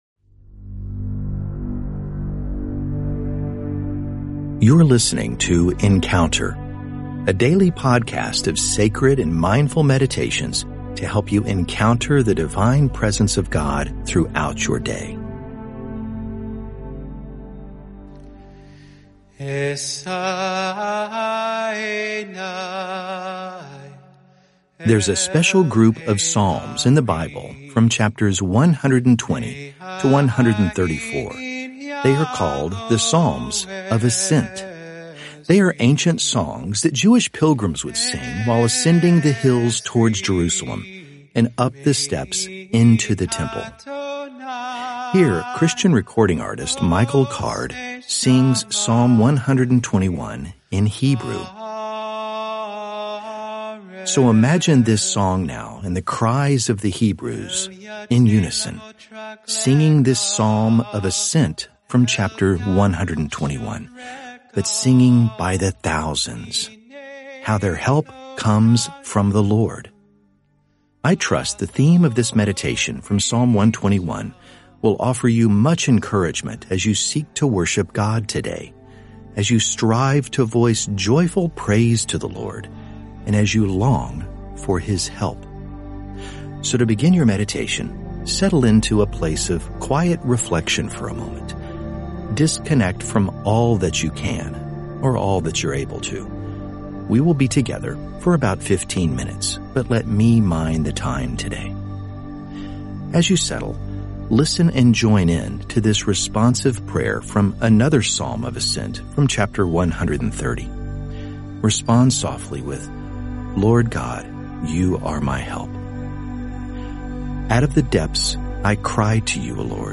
I trust the theme of this guided audio meditation from Psalm 121:1-2 will offer you much encouragement as you seek to worship God today.